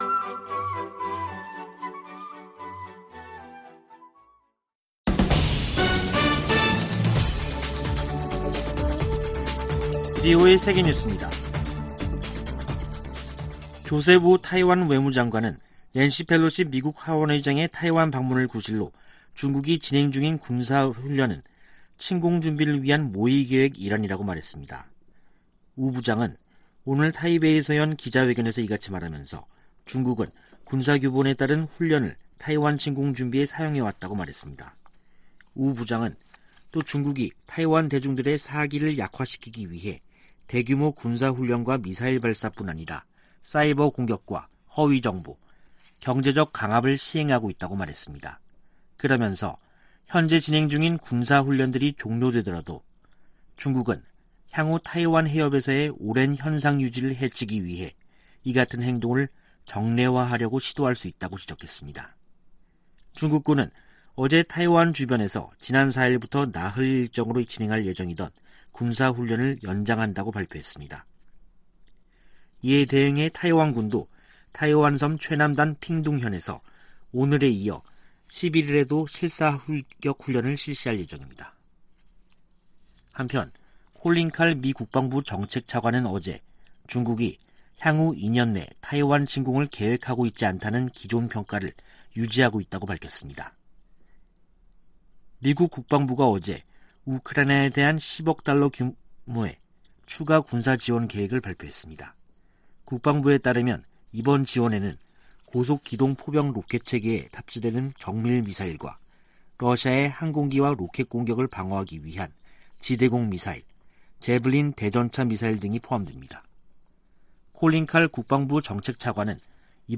VOA 한국어 간판 뉴스 프로그램 '뉴스 투데이', 2022년 8월 9일 2부 방송입니다. 미국 정부가 북한 해킹조직 라자루스가 탈취한 가상화폐의 세탁을 도운 믹서 업체를 제재했습니다. 국무부는 우크라이나 친러시아 세력의 독립인정을 강력히 규탄하며 북한 노동자 파견은 대북 제재 위반이라는 점을 분명히 했습니다. 미 하원의원들이 베트남전쟁에 미군과 함께 참전했던 미국 내 한인들에게 의료 혜택을 제공하는 입법을 촉구했습니다.